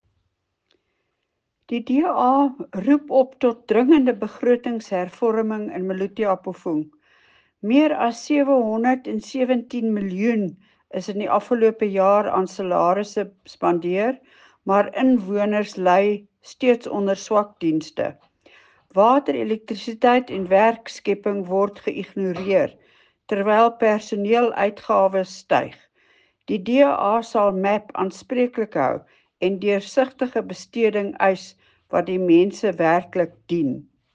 Afrikaans soundbite by Cllr Leona Kleynhans and